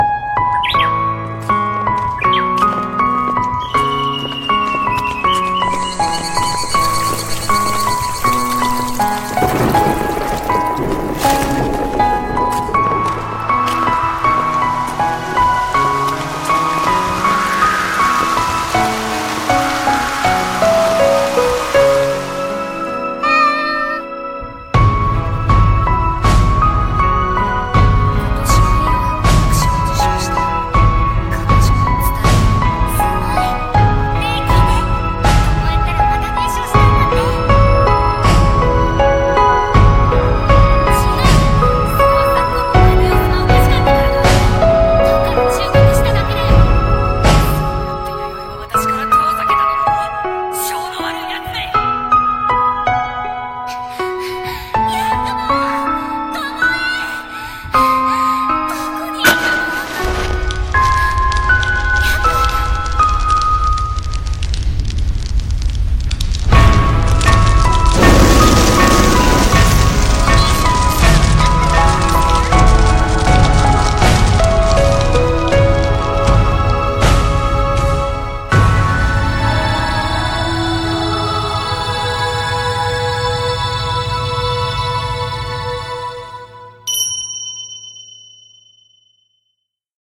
CM風声劇「猫叉逼塞独白譚」